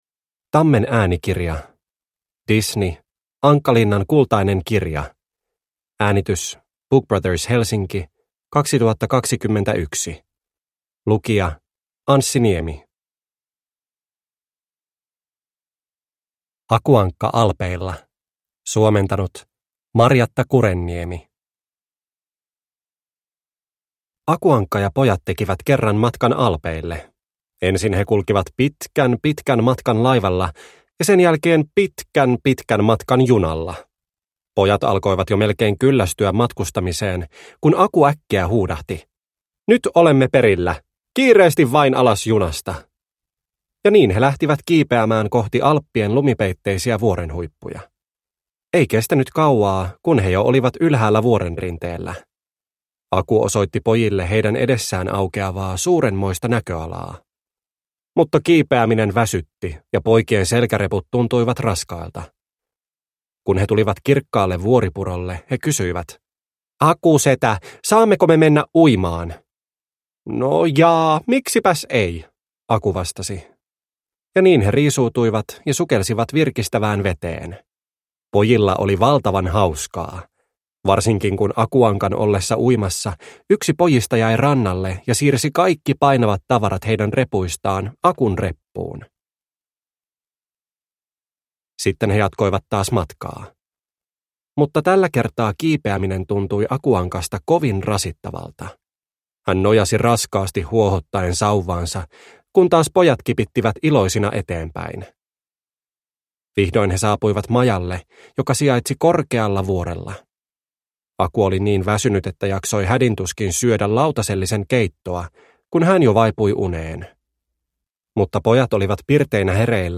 Disney. Ankkalinnan kultainen kirja – Ljudbok – Laddas ner